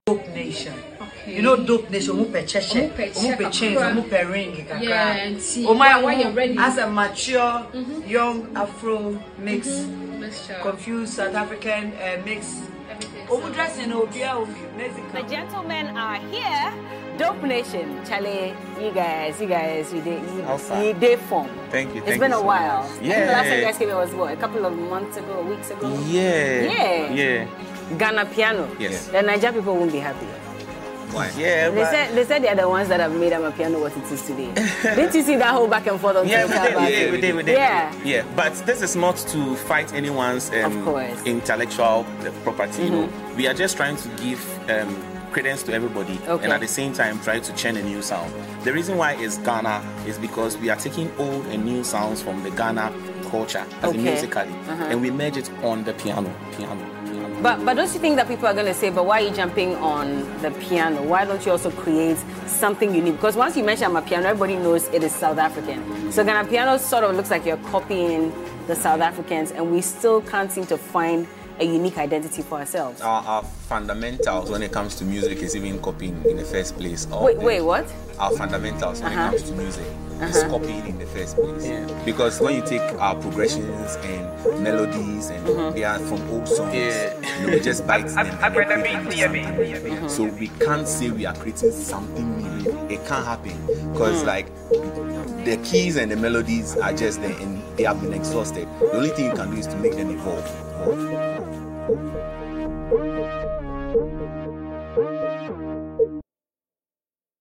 Ghana musical duo of twin brothers
Genre: Amapiano